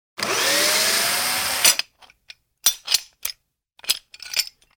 unbolt.wav